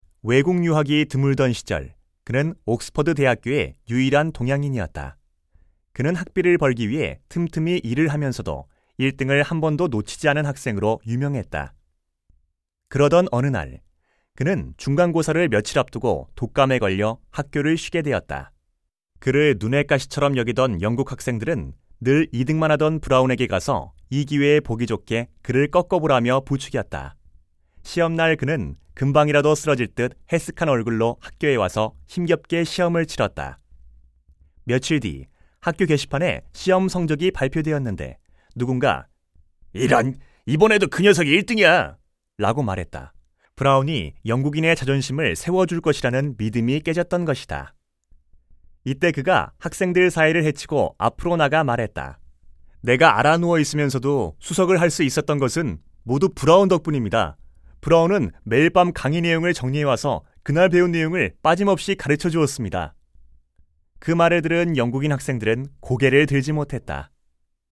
106쪽-내레이션.mp3